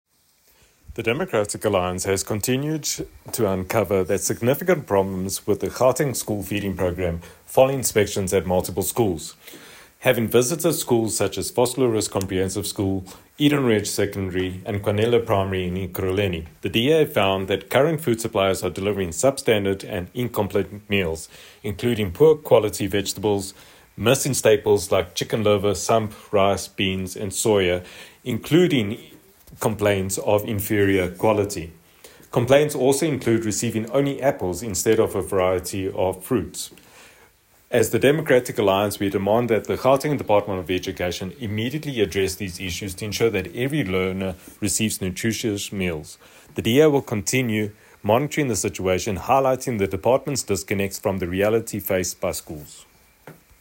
Note to Editors: Please find attached English soundbite by Sergio Isa dos Santos MPL